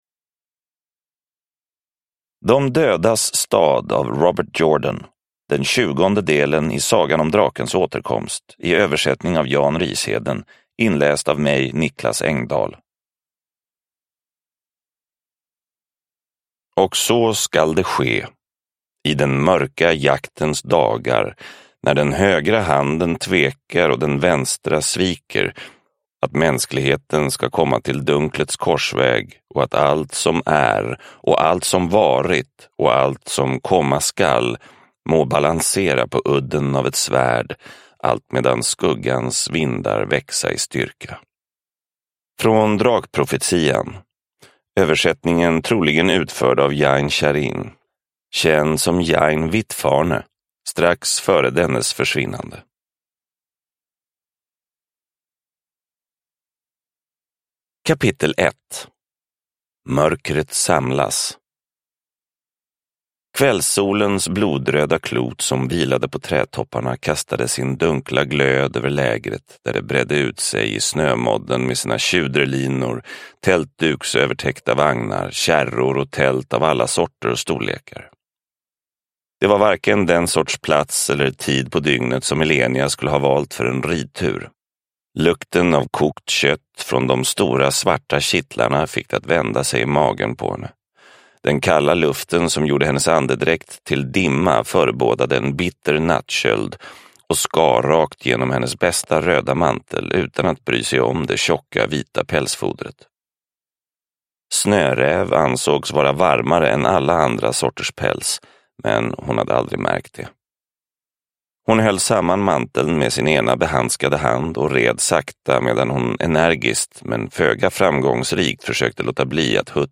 De dödas stad – Ljudbok – Laddas ner